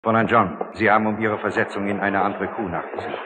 Hörprobe des deutschen Synchronschauspielers (52 Kb)